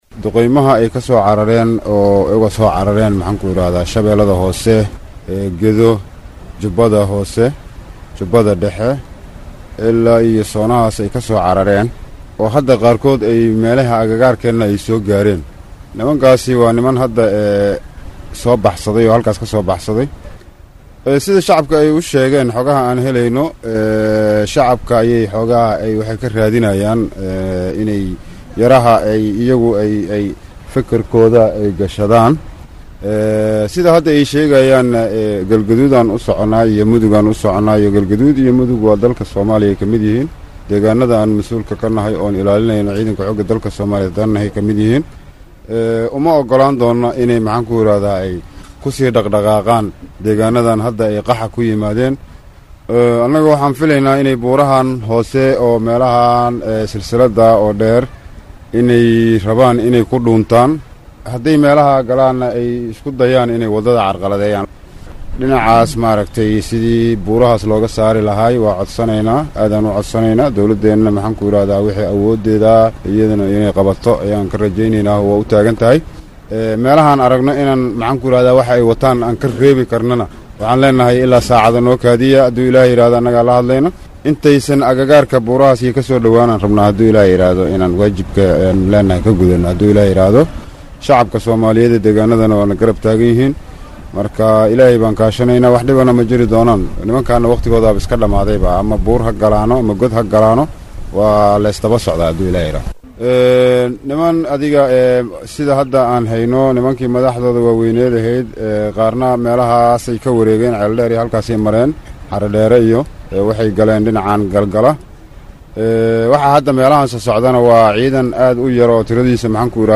Codka-General-Taree-disho.mp3